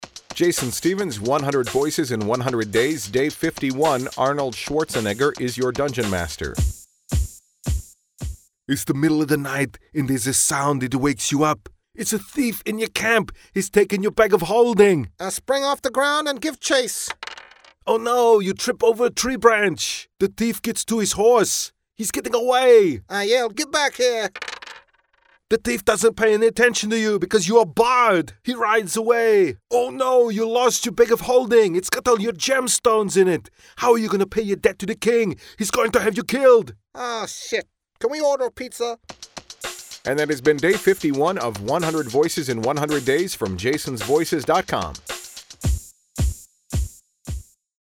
Heading down the home-stretch of the 100 Voices in 100 Days project, and I’m busting out my Arnold Schwarzenegger impression.
Tags: Arnold Schwarzenegger impression, celebrity voices, professional voice overs